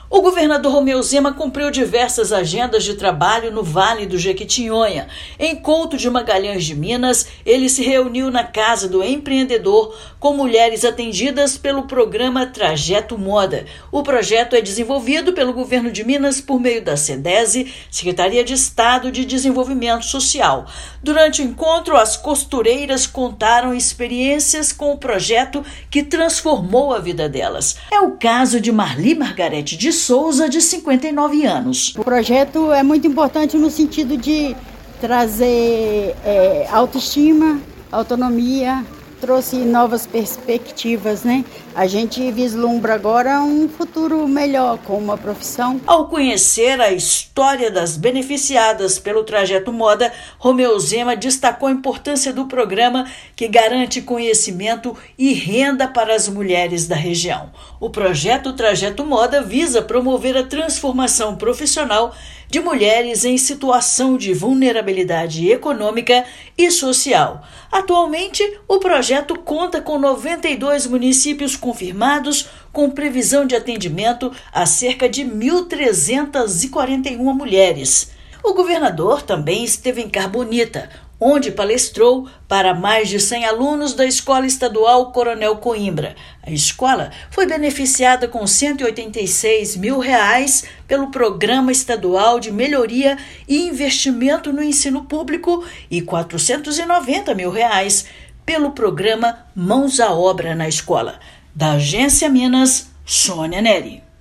Em encontro com o governador, elas contaram como o ofício da costura tem gerado renda e resgate da autoestima. Ouça matéria de rádio.